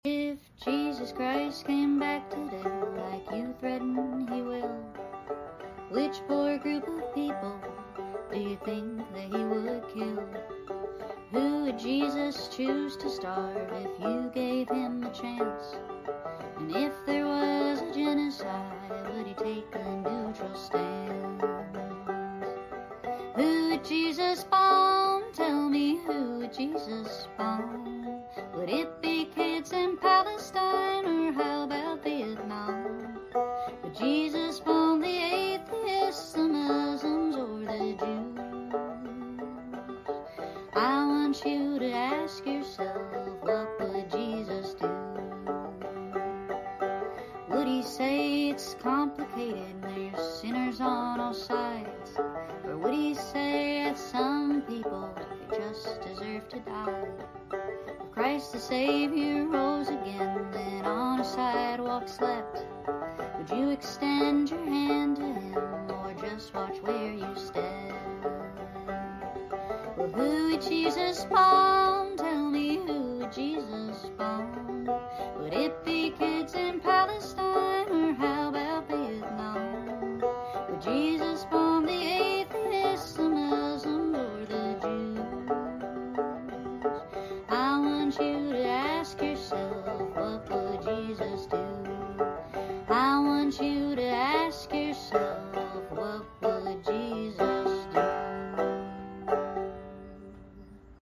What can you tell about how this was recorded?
I know some lyrics got messed up but oh well.